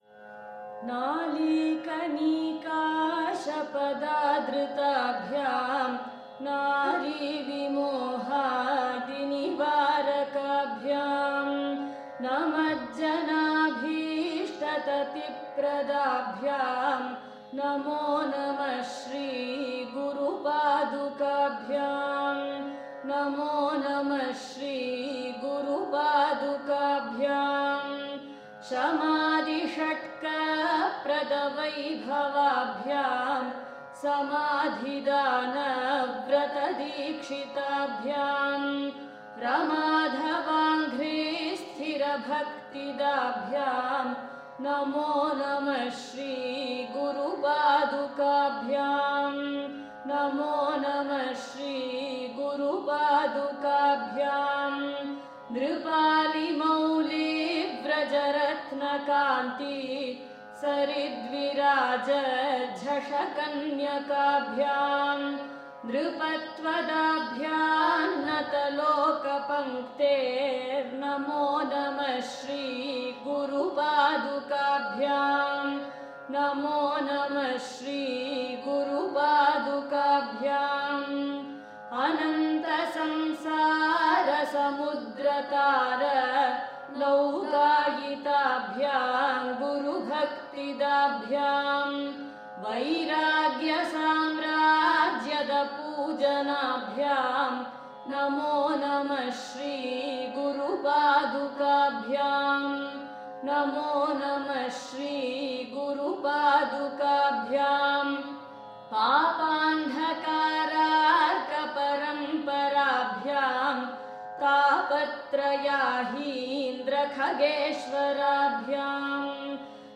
Parayana Audio as per the order